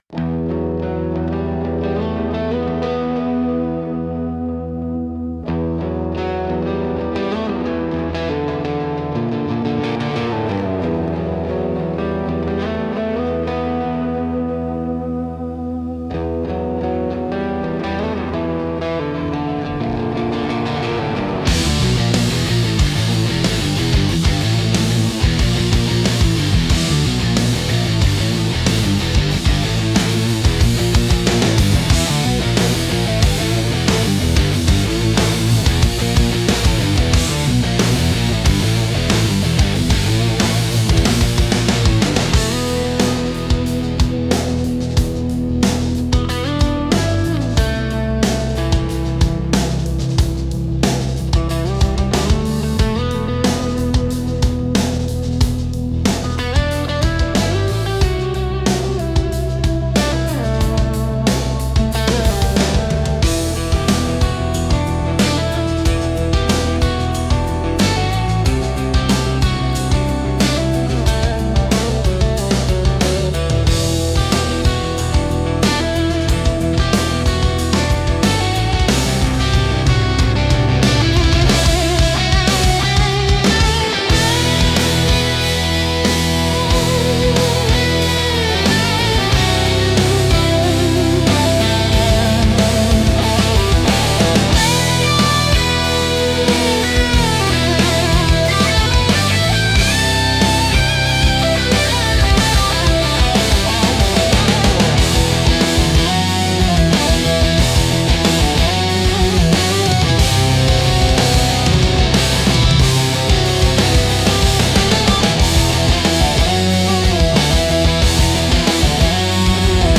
Instrumental - The Space Between the Frets